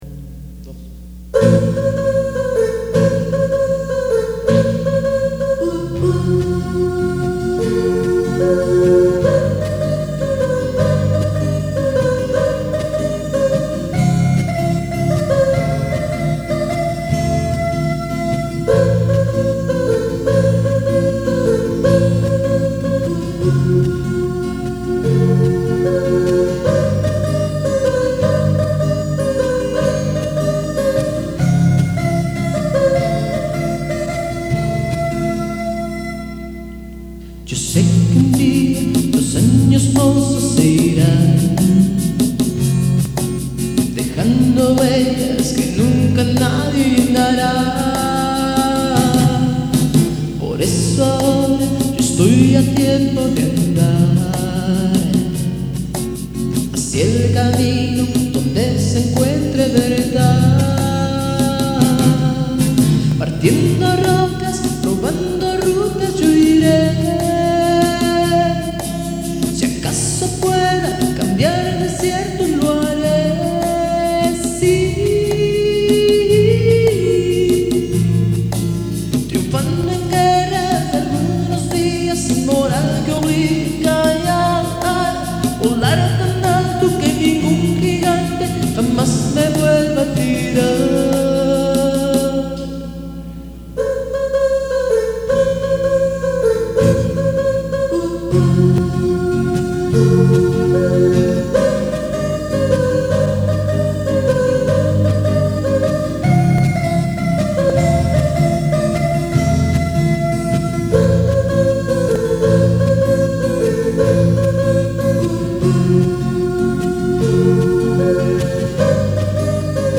Cambiar desiertos Acústica